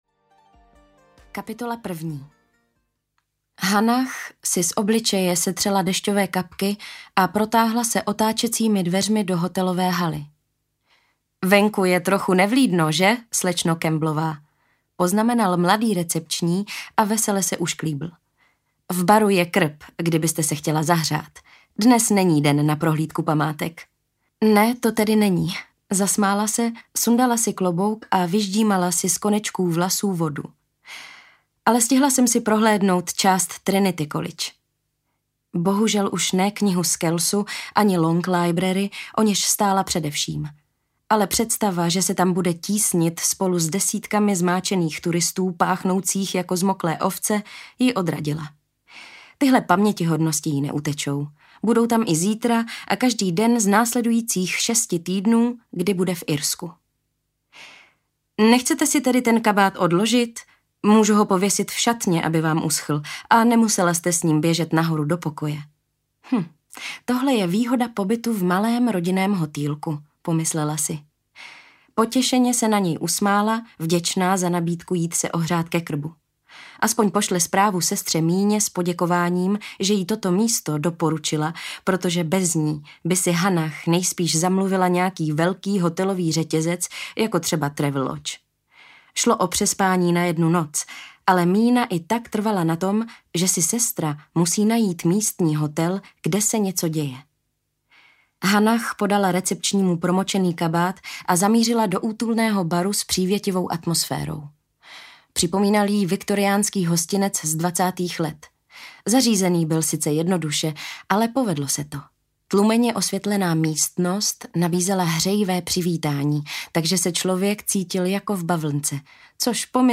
Domek v Irsku audiokniha
Ukázka z knihy